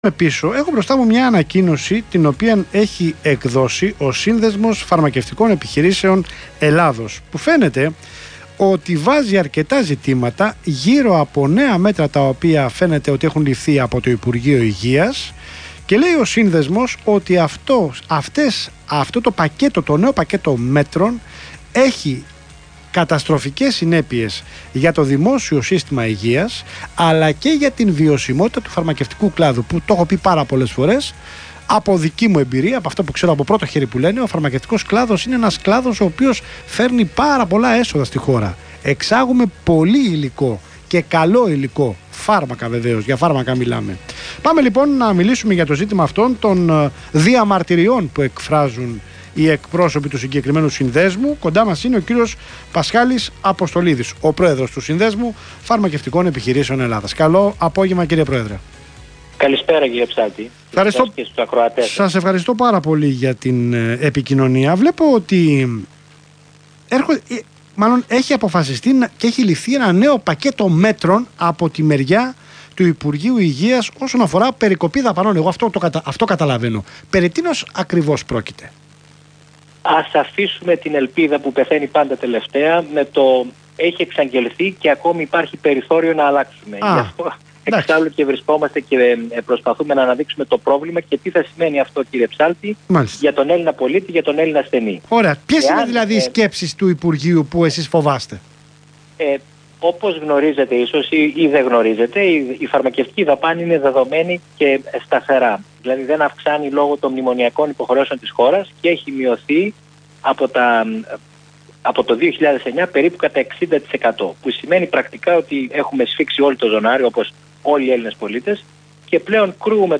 Ηχητικό απόσπασμα από την συνέντευξη